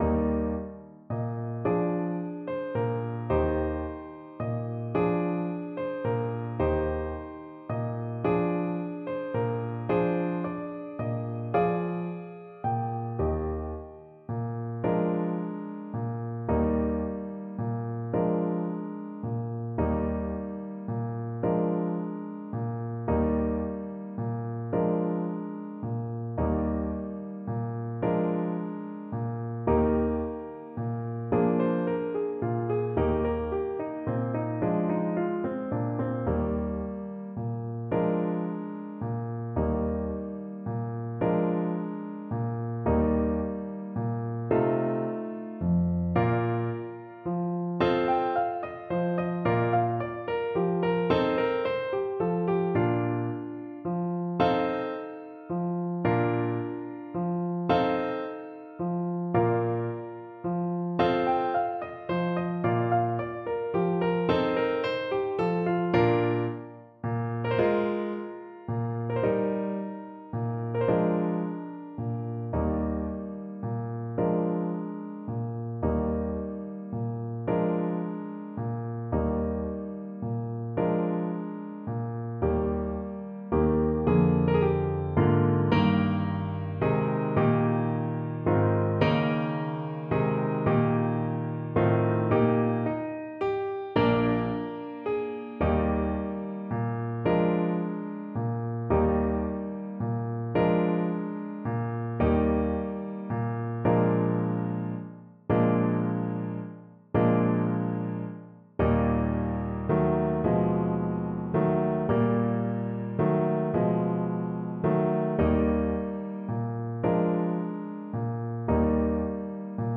Classical Gounod, Charles Sérénade Trumpet version
Moderato quasi allegretto .=c.56
Eb major (Sounding Pitch) F major (Trumpet in Bb) (View more Eb major Music for Trumpet )
6/8 (View more 6/8 Music)
Classical (View more Classical Trumpet Music)